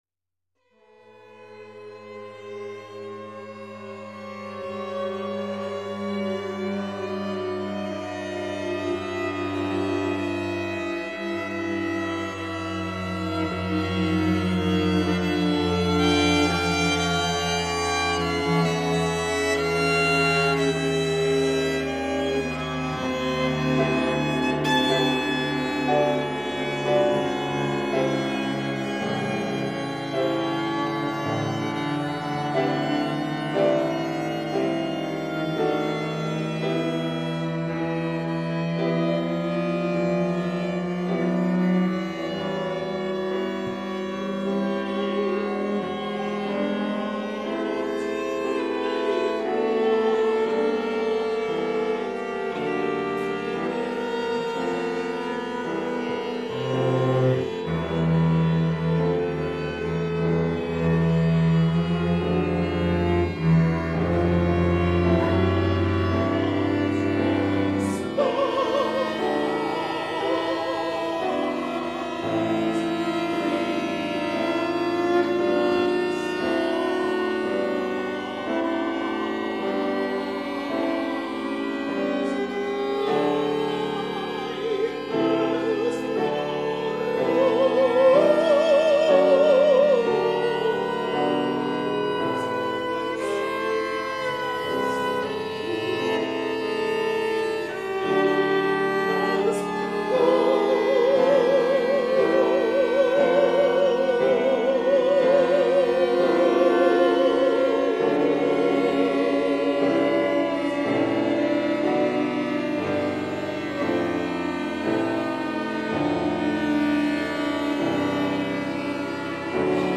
1.Percussion-fingers on the tailpiece(viola) & instrument body(cello)
playing the distinctive percussion effects